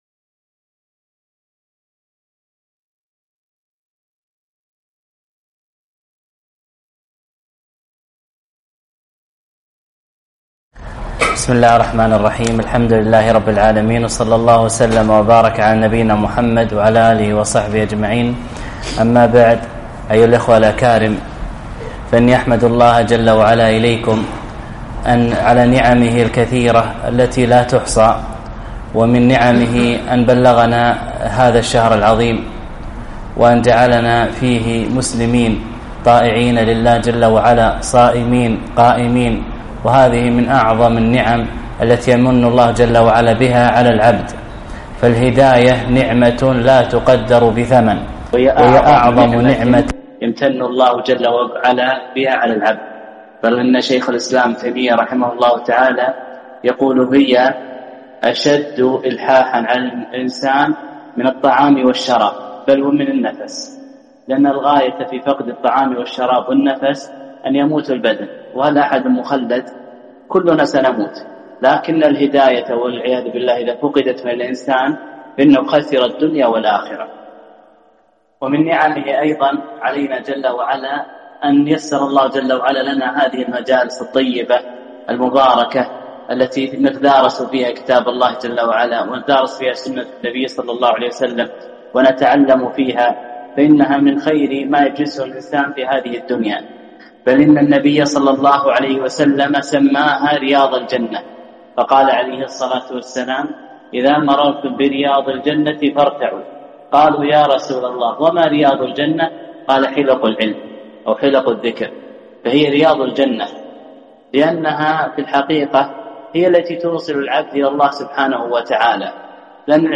ألقيت بعد التراويح